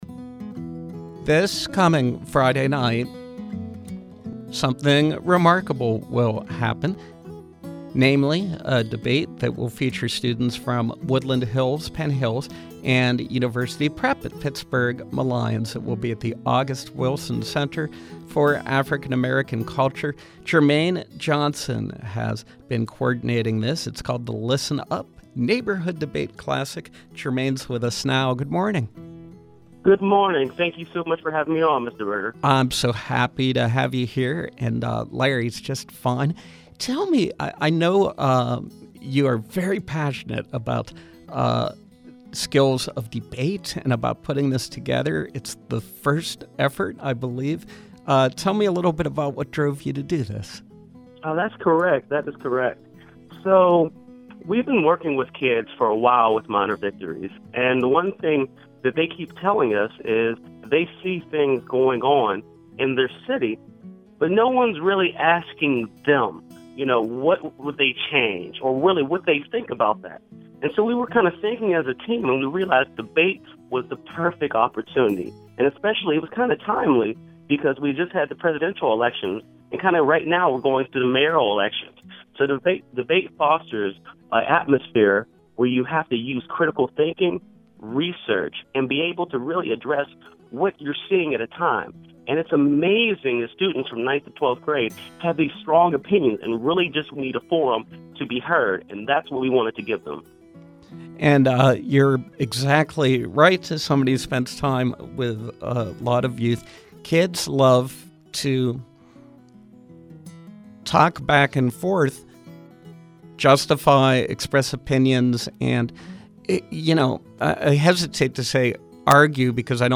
Interview: Listen Up! Neighborhood Debate Classic